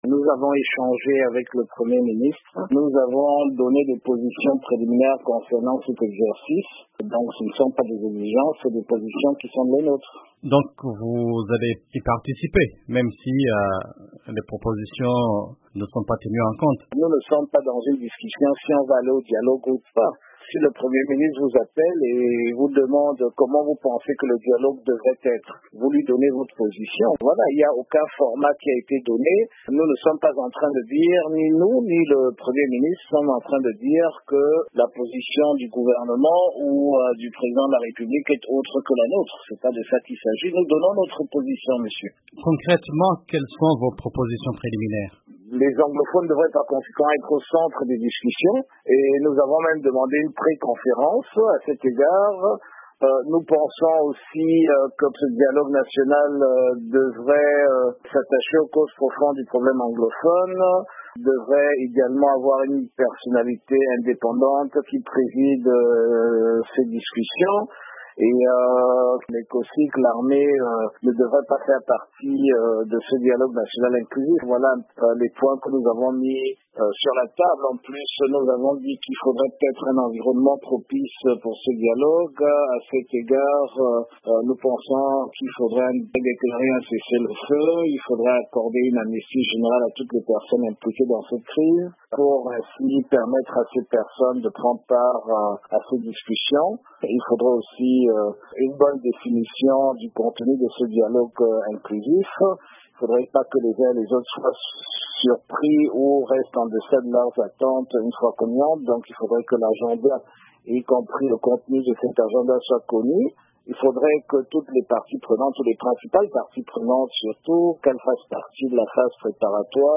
Au Cameroun, le principal parti de l'opposition, le Front Social Démocrate, SDF, a exigé une "amnistie générale" des détenus liés à la crise séparatiste de l'ouest anglophone et "un cessez-le-feu" avant de participer au "grand dialogue national" proposé par le président Paul Biya. Interview de Joshua Osih, vice-président du SDF.